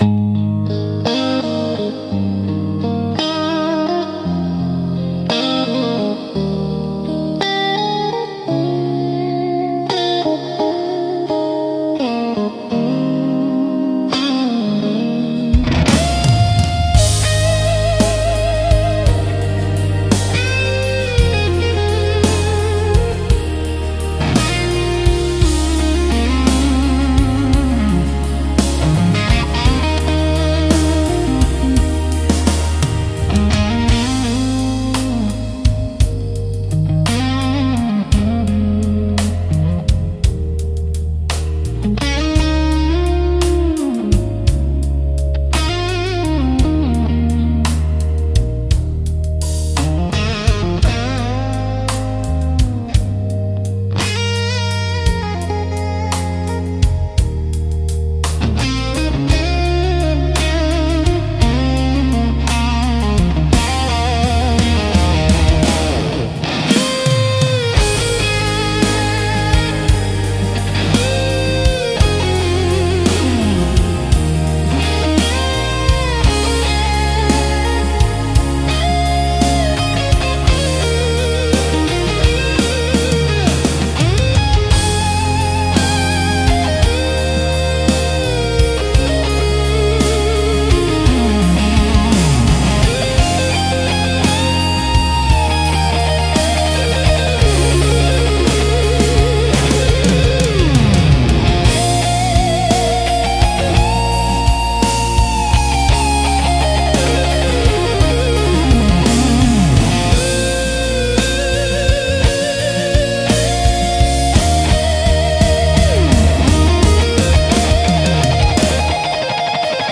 しっとりとした雰囲気を求める場面で活用されるジャンルです。